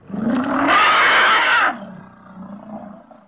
جلوه های صوتی
دانلود صدای حیوانات جنگلی 35 از ساعد نیوز با لینک مستقیم و کیفیت بالا